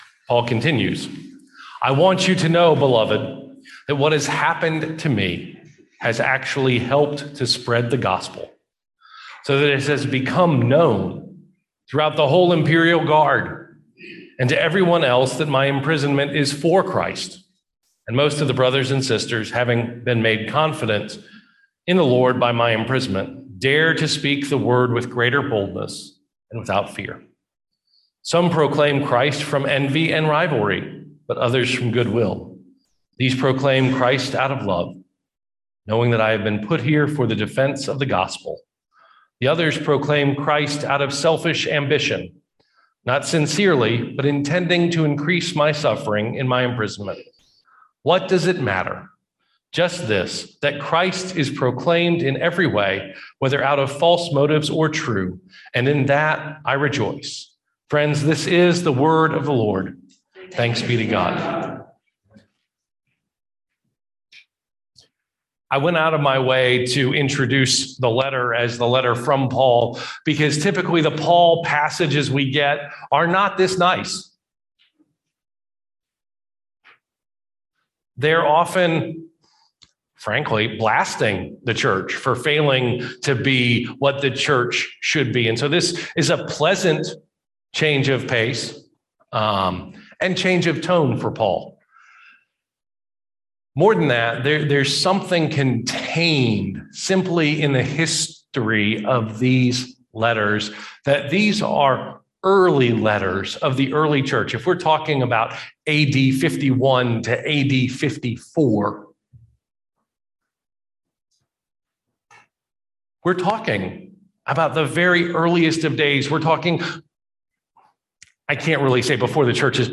Worship 2022